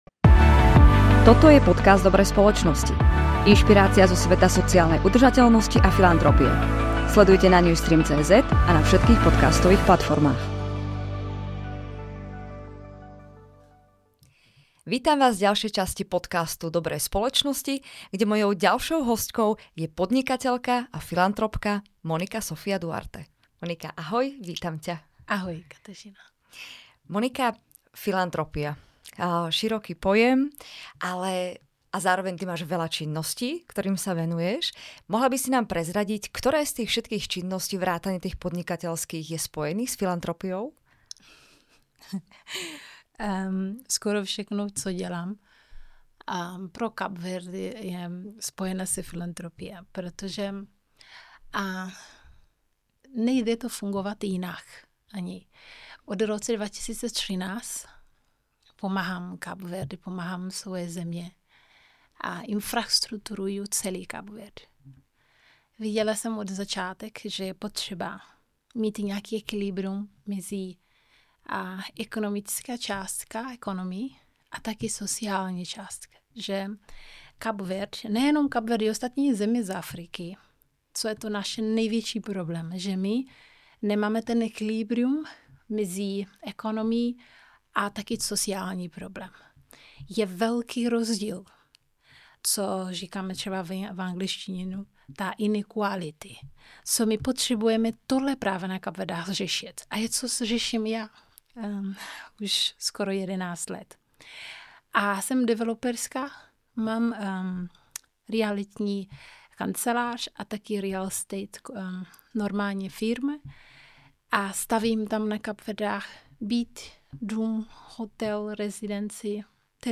podcastové série rozhovorů